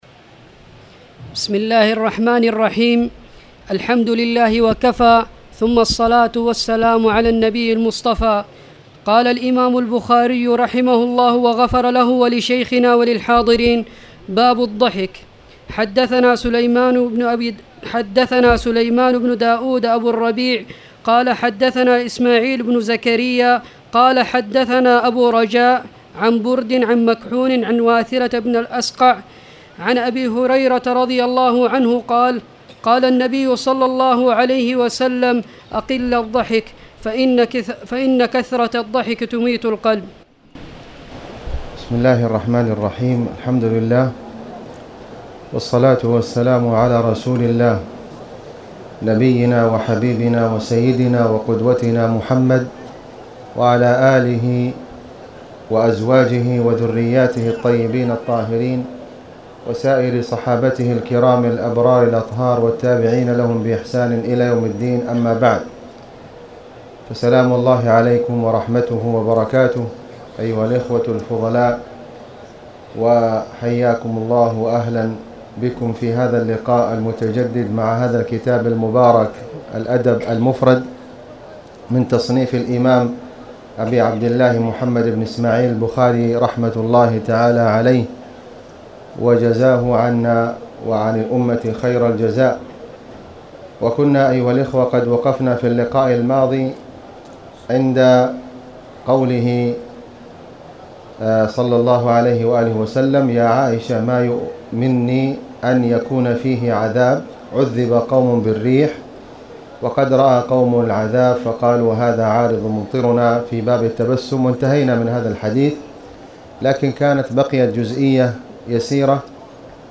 تاريخ النشر ٤ ربيع الثاني ١٤٣٨ هـ المكان: المسجد الحرام الشيخ: فضيلة الشيخ د. خالد بن علي الغامدي فضيلة الشيخ د. خالد بن علي الغامدي باب الضحك